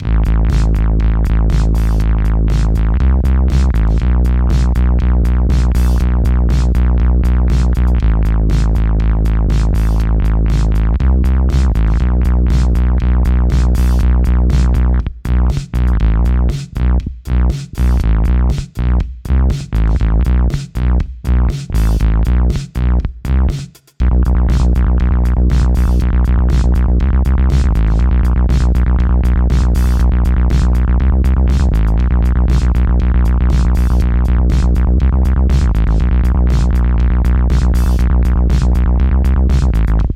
I then play at various speeds @ 120bpm to see how fast the filter retrigger is. All playing is open, no palm muting.
korg_g5_120bpm_speed_test.mp3